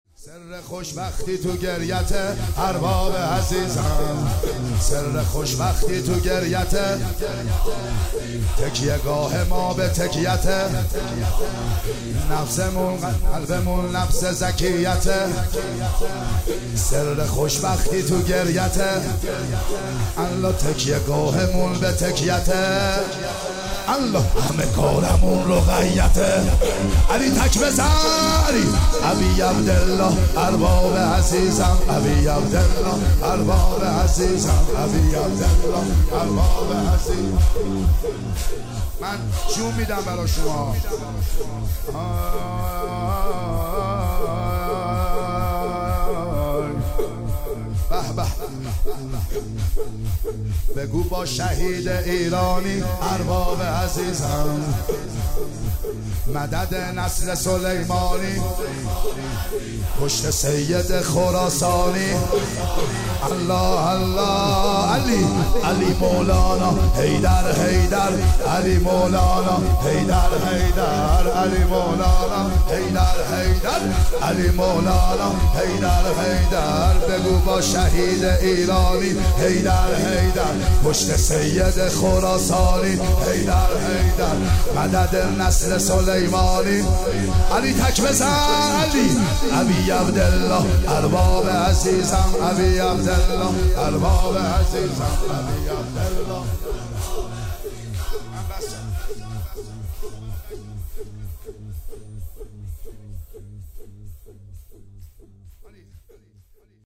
شب چهارم فاطمیه دوم صوتی
شب چهارم فاطمیه دوم صوتی شور همه کارمون رقیه است محمد حسین حدادیان